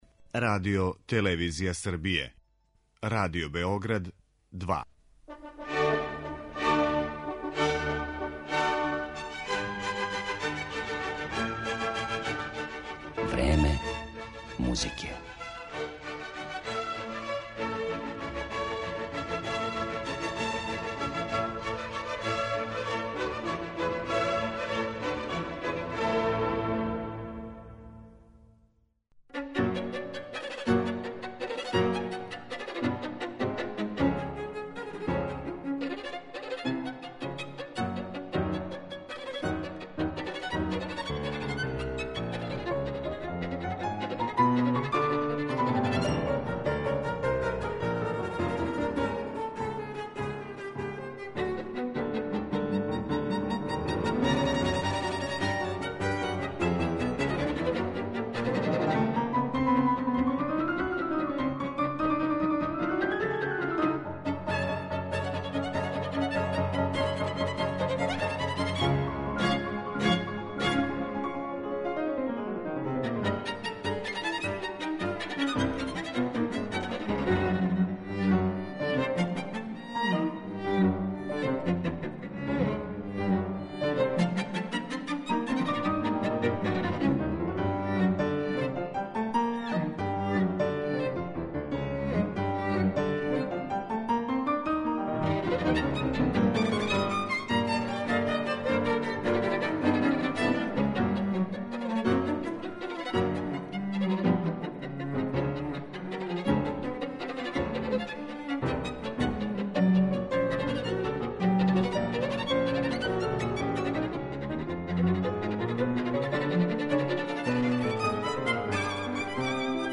Више од пола века је чувени британски камерни ансамбл Les beaux arts trio заузимао место једног од најбољих на свету у овом жанру.